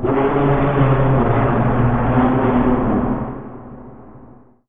cavern_monster_1.wav